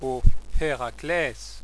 Ð `HraklÁj, šouj[E]
La prononciation ici proposée est la prononciation qui a cours actuellement, en milieu scolaire, en France.